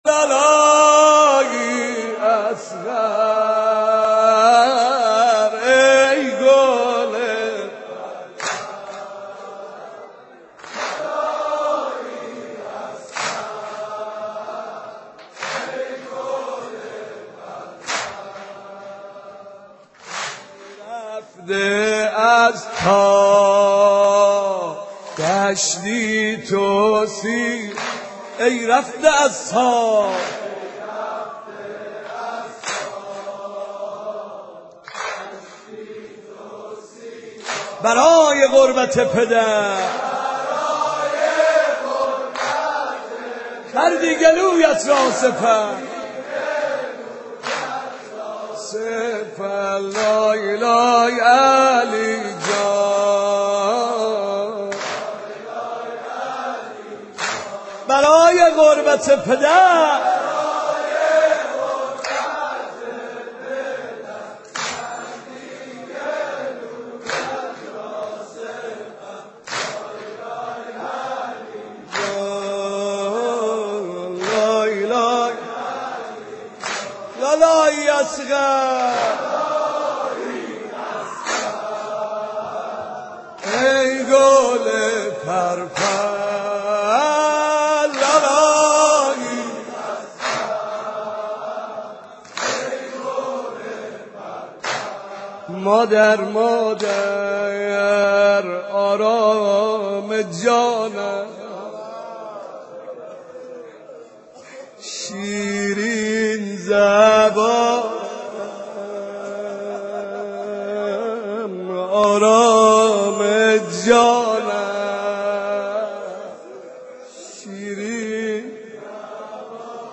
محرم شب هفتم ، حضرت علی اصغر علیه السلام مداح اهل بیت استاد